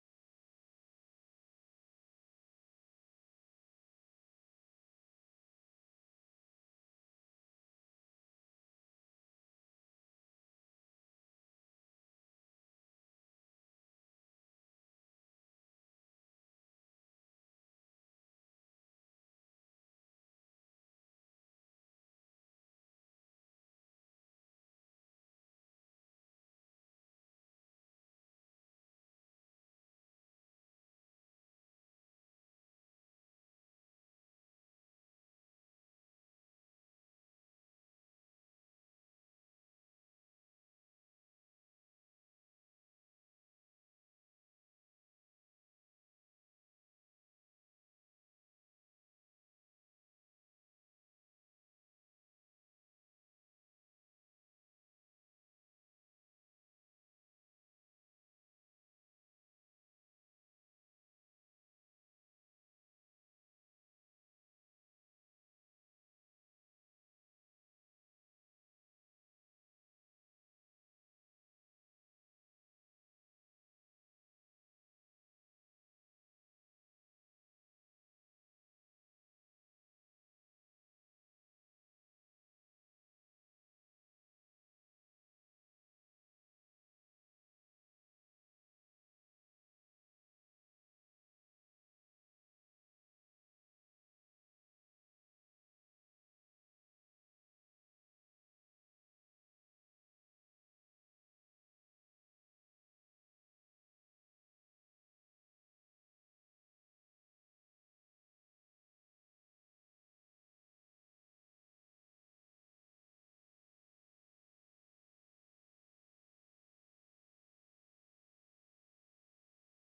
Sermons from the morning and evening worship services of church of Christ in Olive Branch, Mississippi USA.